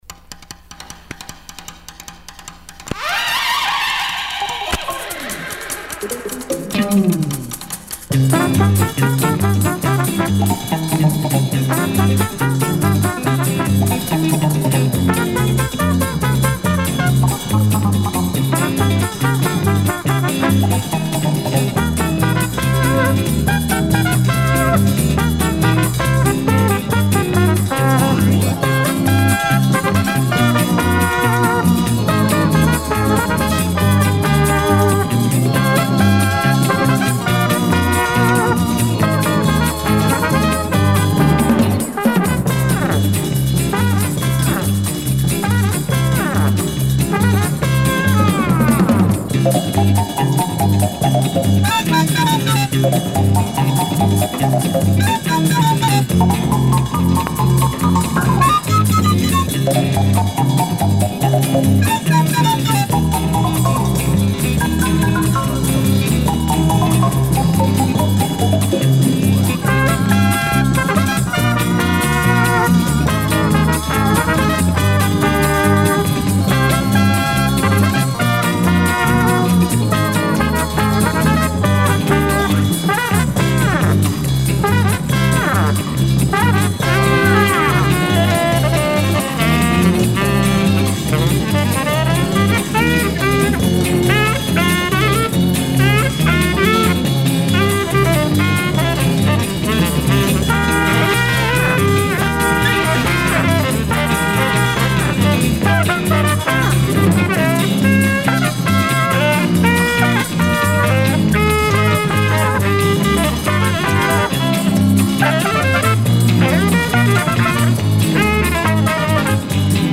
инструменталу